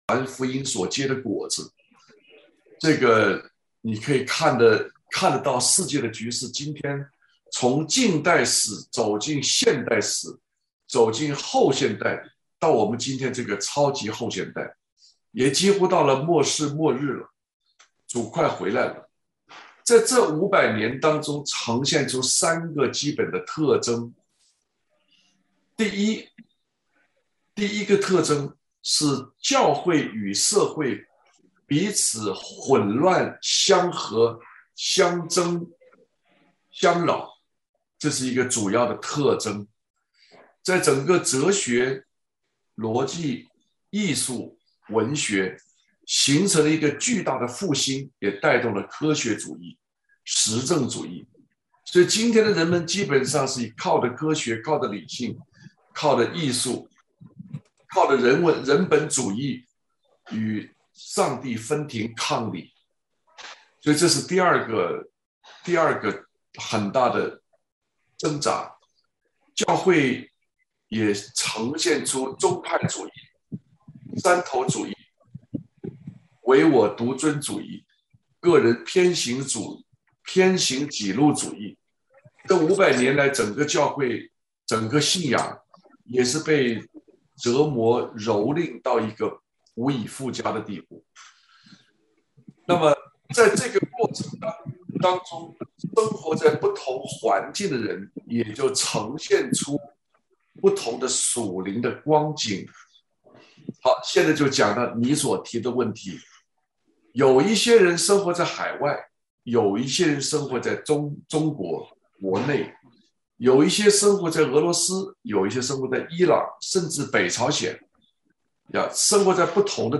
主日讲道